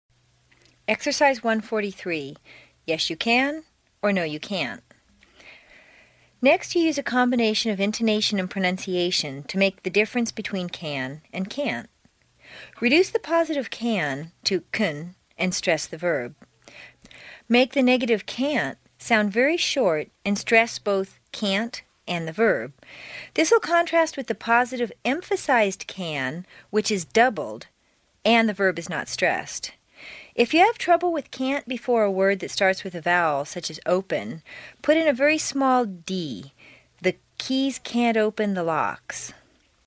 在线英语听力室美式英语正音训练第36期:Exercise 1-43 You Can or No的听力文件下载,详细解析美式语音语调，讲解美式发音的阶梯性语调训练方法，全方位了解美式发音的技巧与方法，练就一口纯正的美式发音！